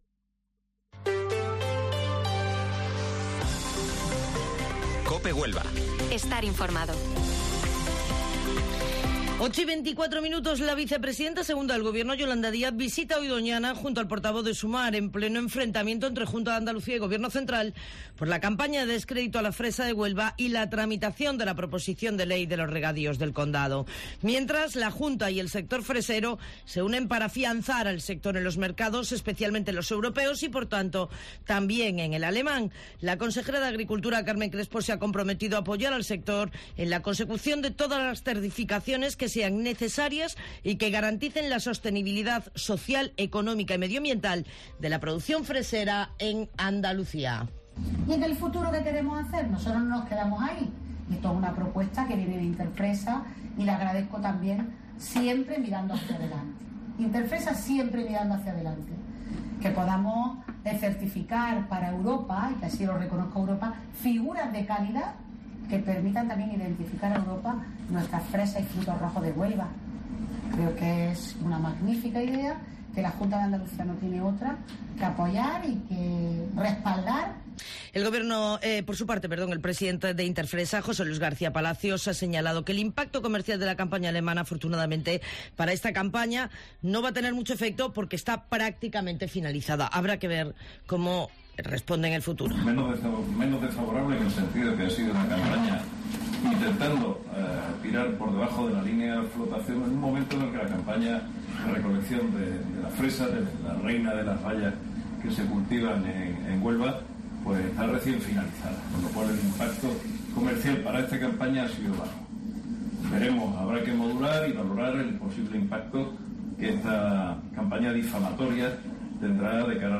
Informativo Matinal Herrera en Cope 7 de junio